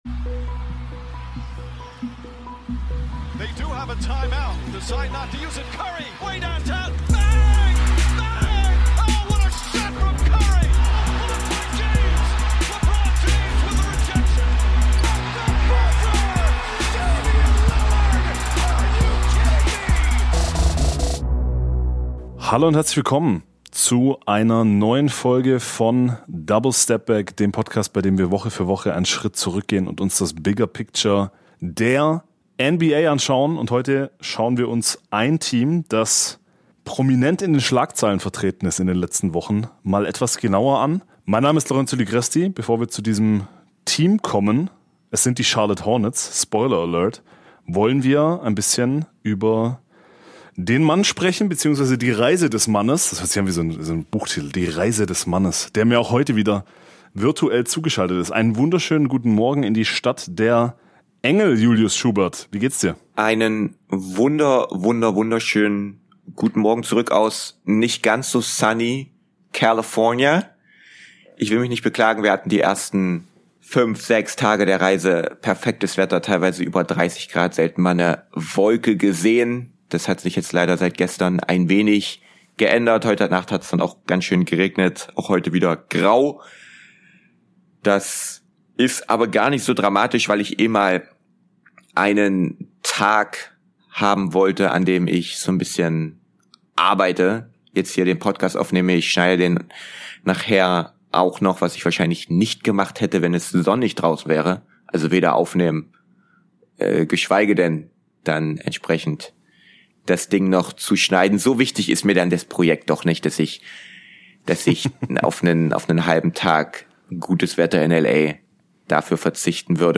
Isaiah Hartenstein im Exklusiv-Interview + Wie legit sind die Hornets?